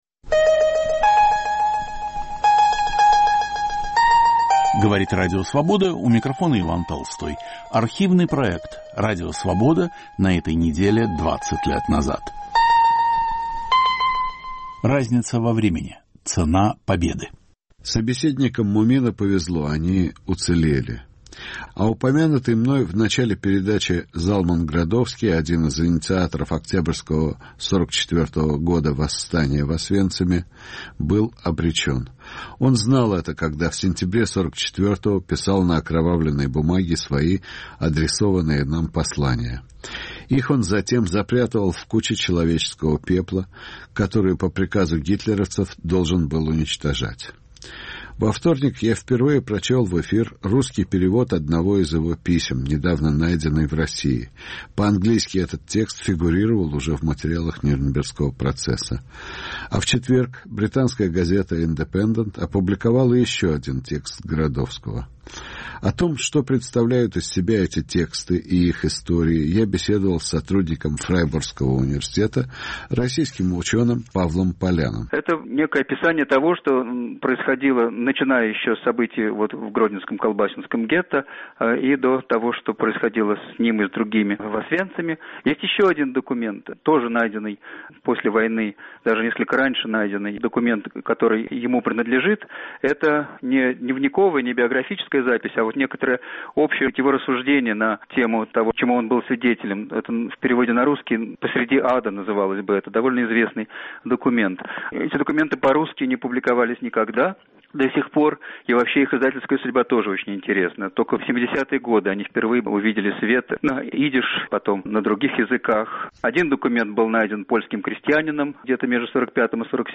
Рассказывают выжившие заключенные.